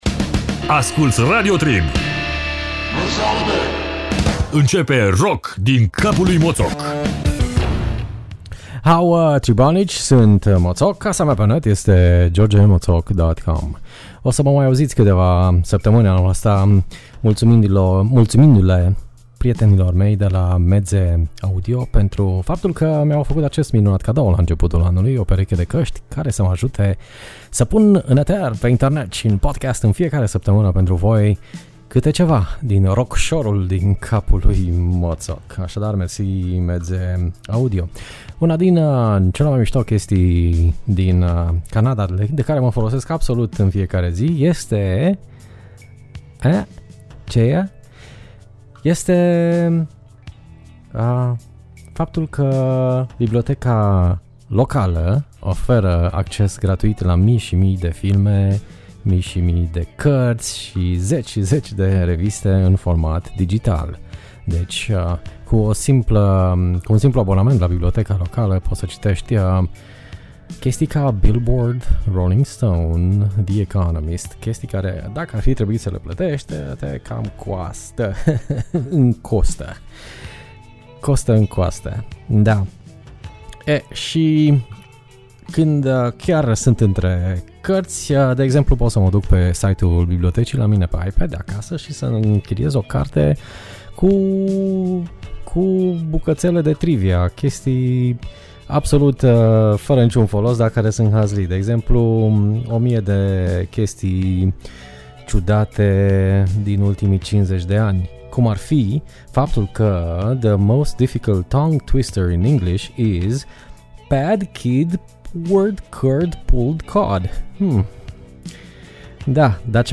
efecte de sintezaizer si science fiction